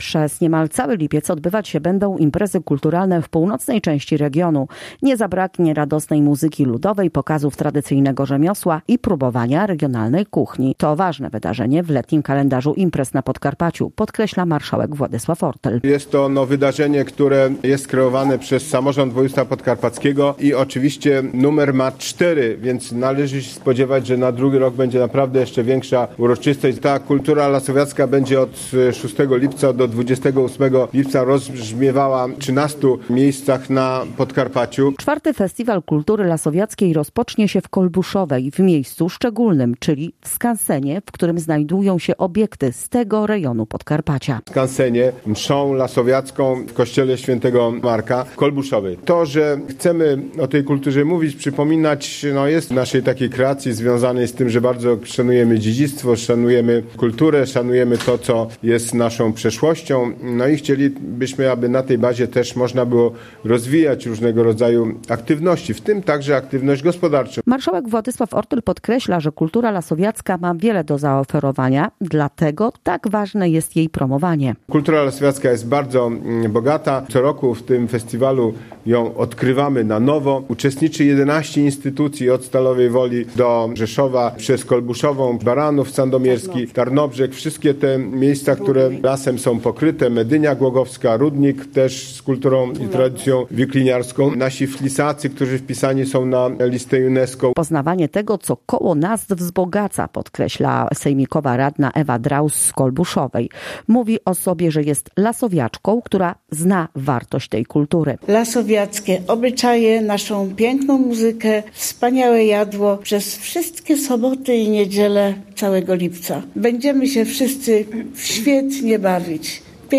Marszałek Władysław Ortyl podkreśla, że to ważne wydarzenie, które już na stałe wpisało się w kalendarz letnich wydarzeń naszego regionu.
Relacja